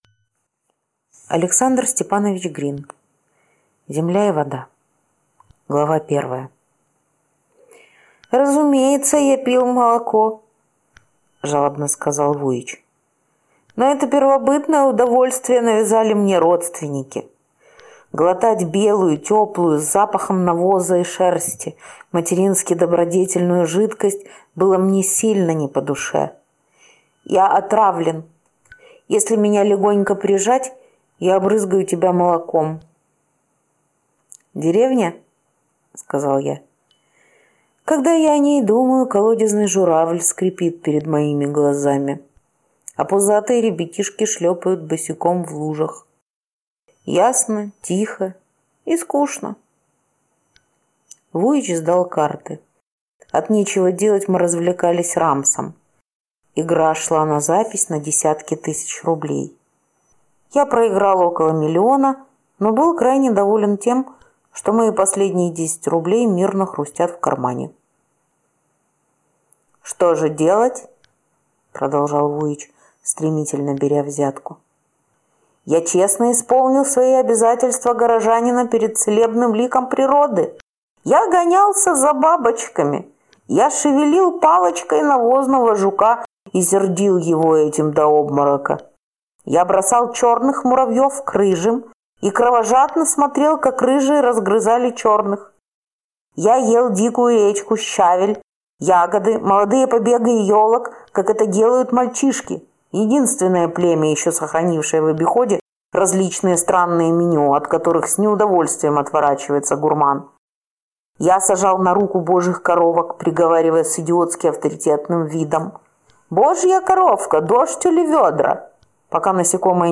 Аудиокнига Земля и вода | Библиотека аудиокниг